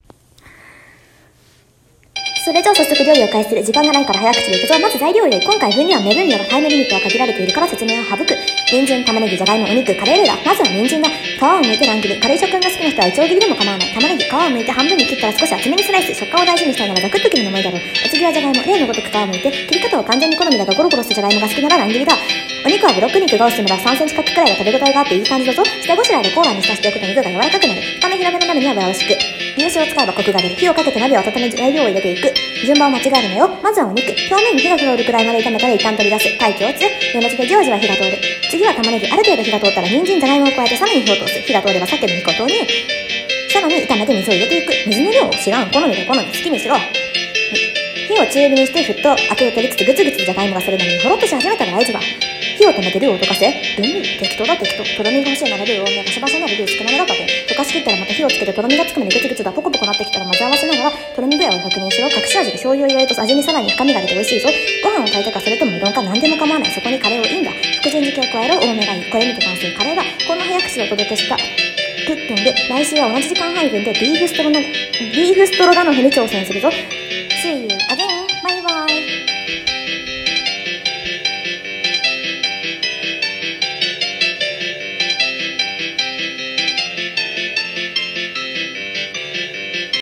【一人声劇】【早口】